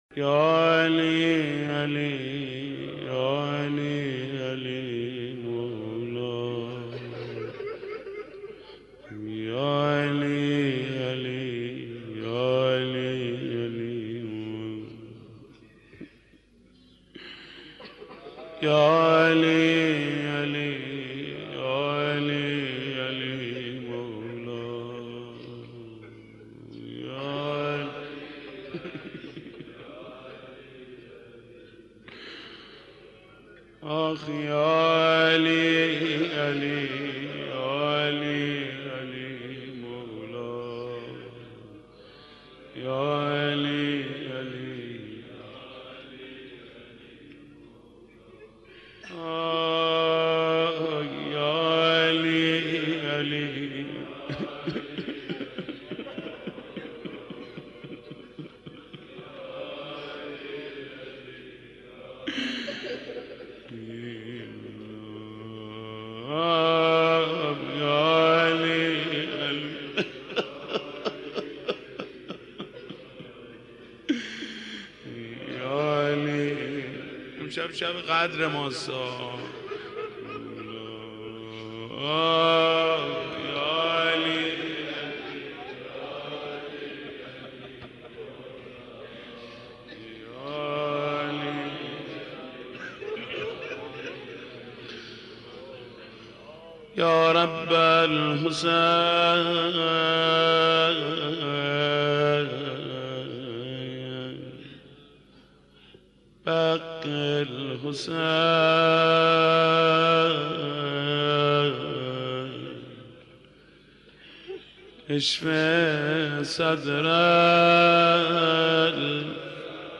مناسبت : تاسوعای حسینی
مداح : محمود کریمی